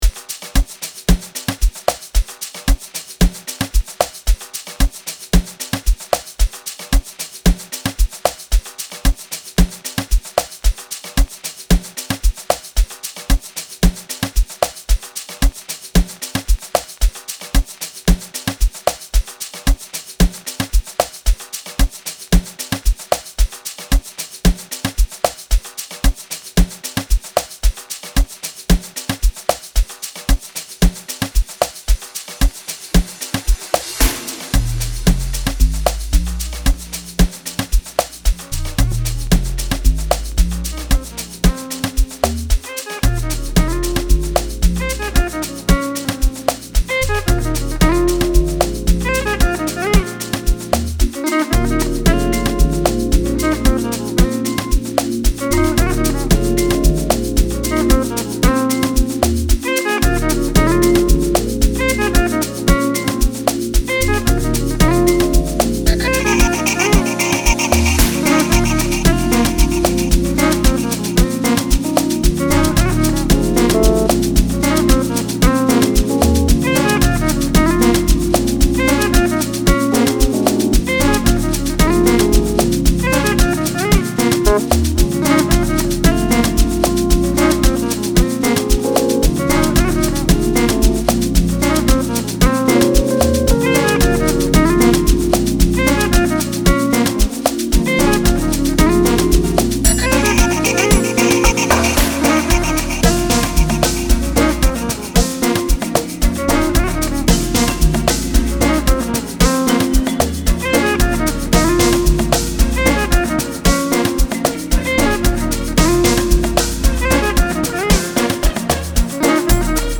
2024 Categoria: Amapiano Download RECOMENDAÇÕES